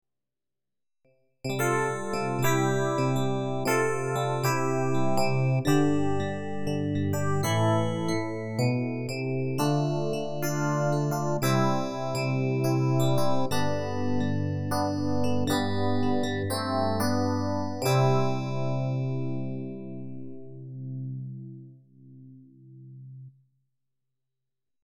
Sachant que les sons que tu nous as fait écouter sont traité avec des effets style reverbe etc.
Mais il s'agit bel et bien de sons typiquement FM.
Rien que des sons FM très classiques et tout à fait reproductibles sur un DX7.